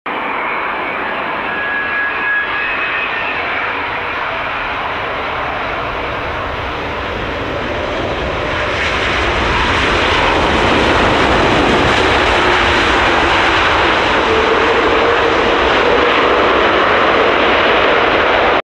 Kuwait Neo Touchdown ✈ Sound Effects Free Download